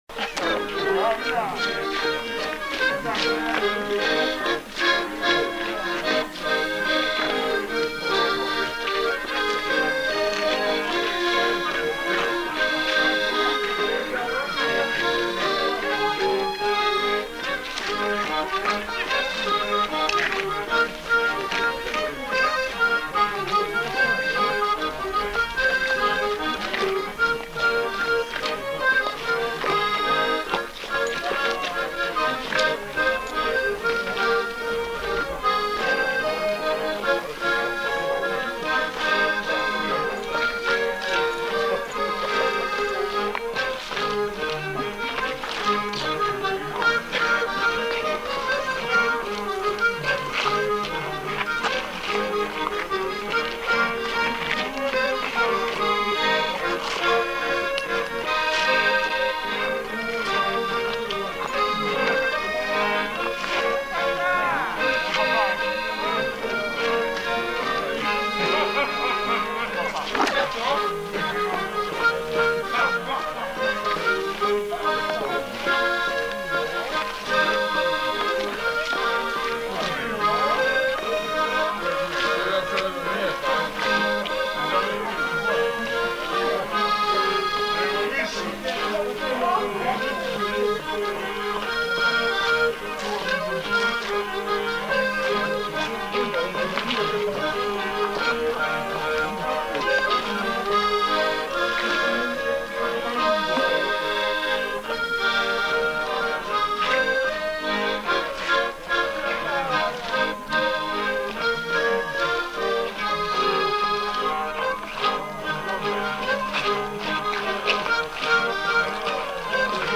Scottish
Aire culturelle : Savès
Lieu : Espaon
Genre : morceau instrumental
Instrument de musique : accordéon diatonique
Danse : scottish
Notes consultables : Coupure avant la fin du morceau.